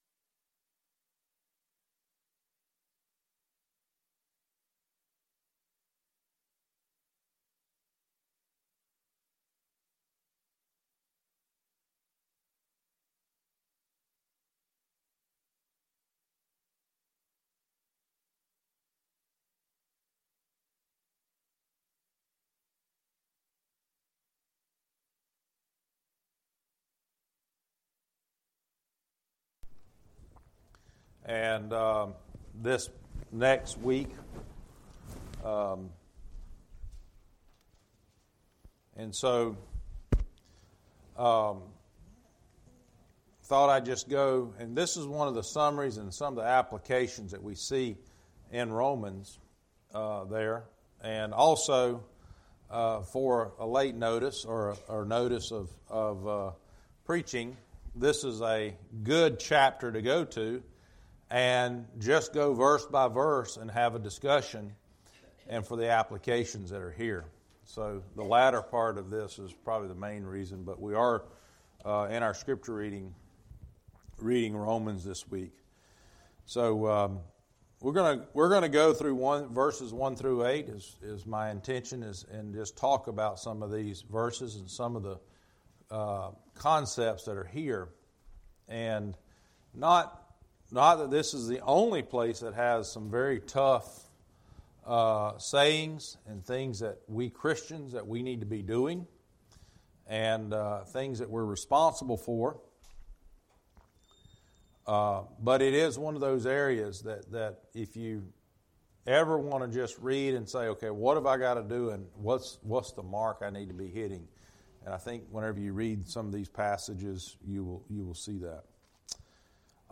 November 24, 2024 | No Comments | Sermon Mp3s What Is The Mark I Need To Be Hitting?